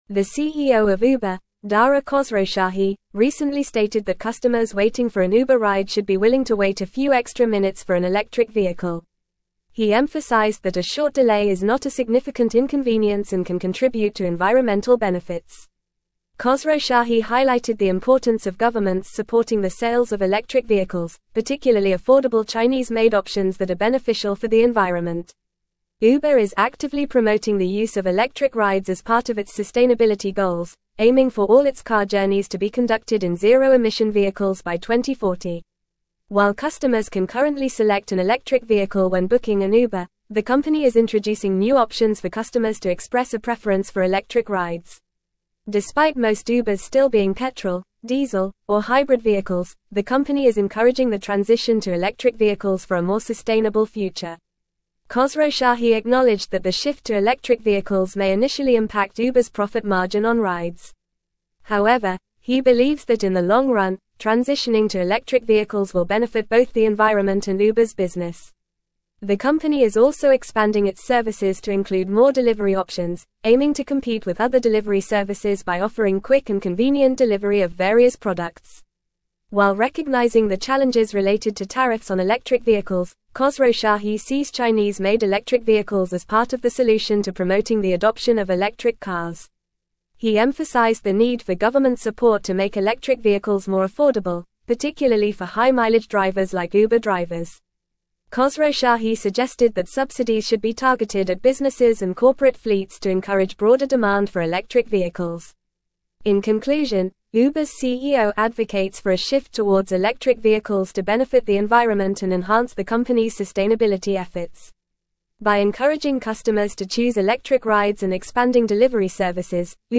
Fast
English-Newsroom-Advanced-FAST-Reading-Uber-CEO-Urges-Patience-for-Electric-Rides-Transition.mp3